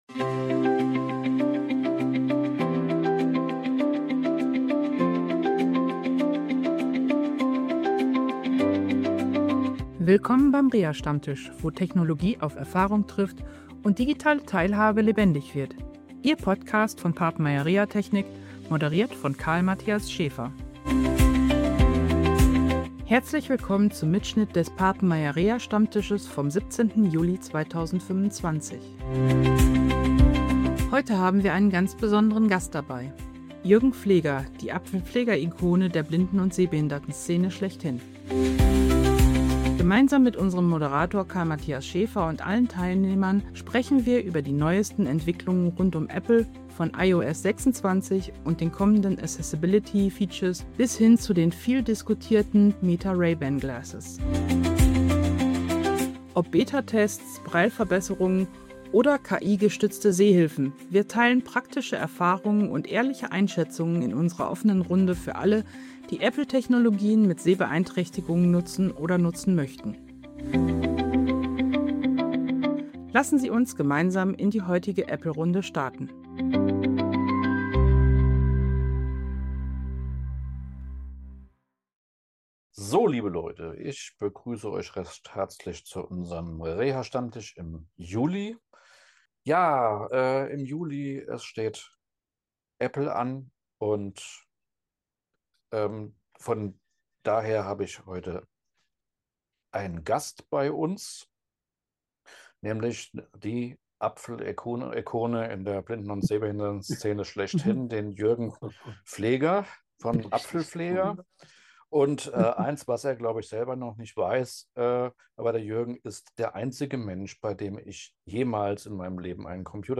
Die offene Gesprächsrunde ermöglichte allen Teilnehmern, eigene Erfahrungen einzubringen und Fragen zu stellen.